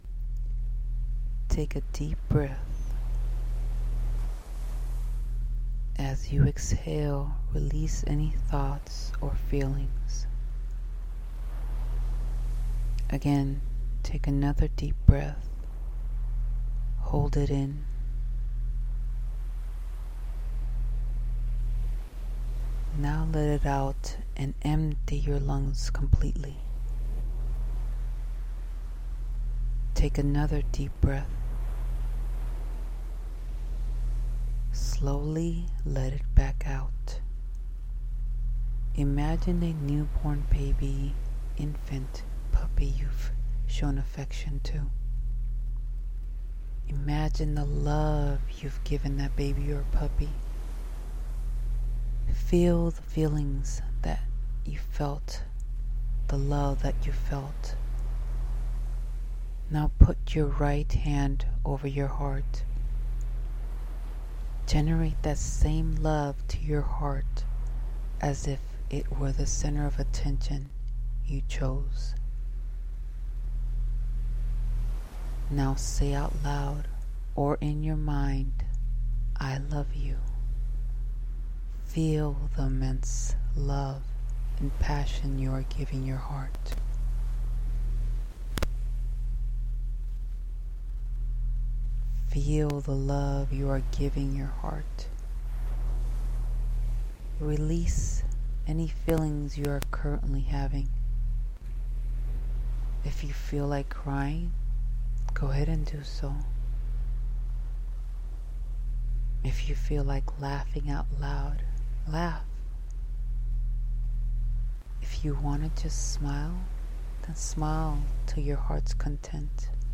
Heart Meditation
heartMeditation-1.mp3